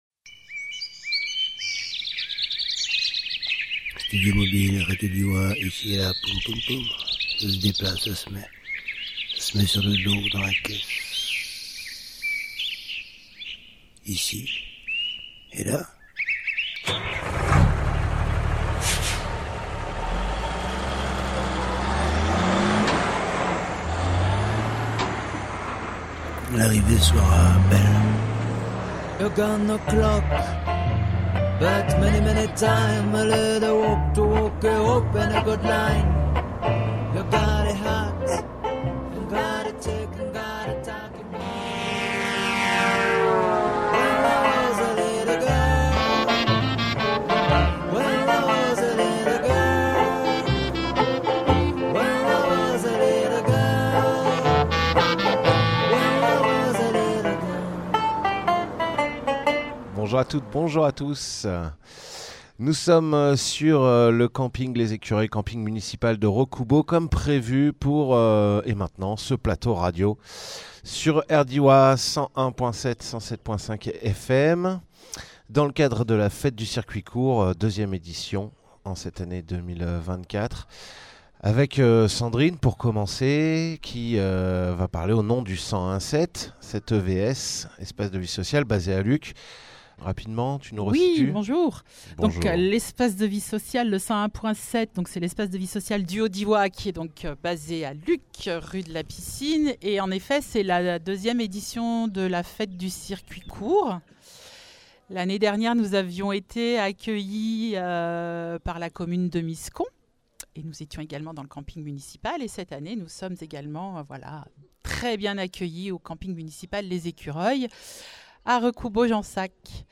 Avec aussi une table-ronde radiophonique, l’occasion de parler d’ alimentation et d’autonomie, de production locale et d’économie circulaire, de gestion de l’eau et d’agriculture.
Jérôme Mellet, maire de Luc-en-Diois
Lieu : Camping municipal « Les Écureuils » à Recoubeau-Jansac